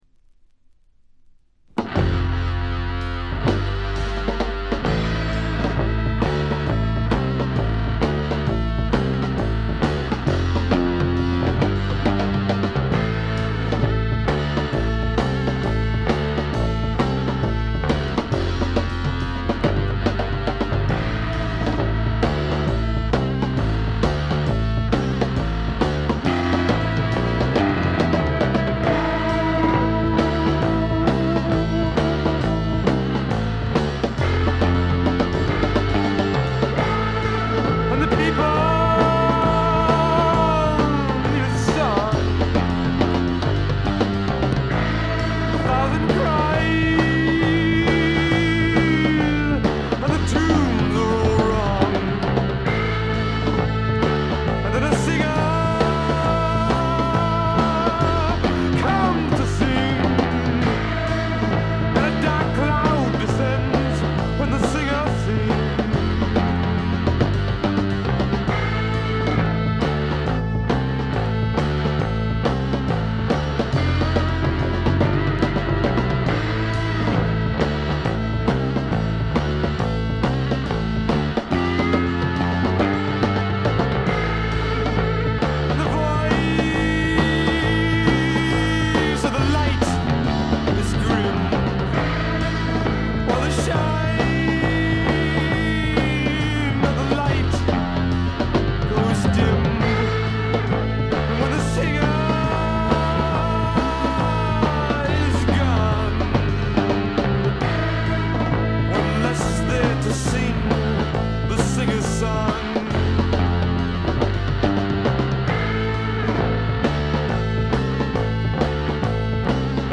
バックグラウンドノイズ、チリプチ多め大きめ。
マサチューセッツの5人組で男女ヴォーカルをフィーチャーしたジェファーソン・エアプレイン・タイプのバンドです。
試聴曲は現品からの取り込み音源です。